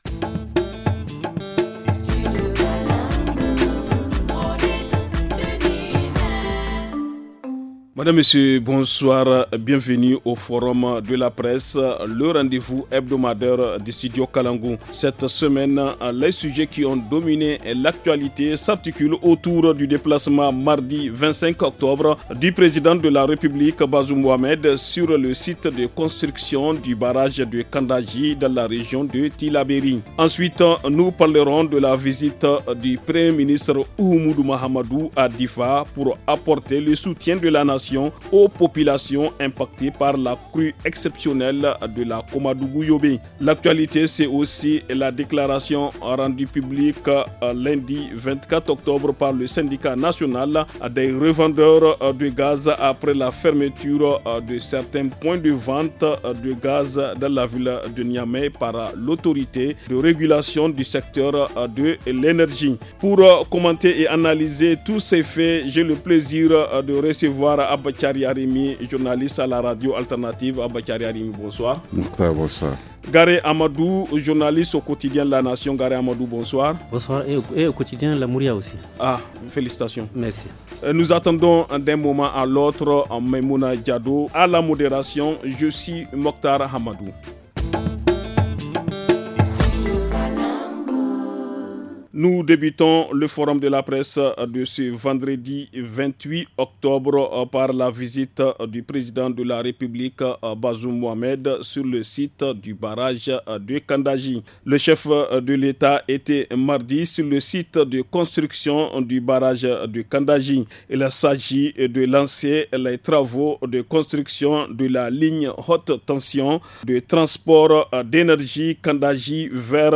Pour commenter et analyser tous ces faits, j’ai le plaisir de recevoir :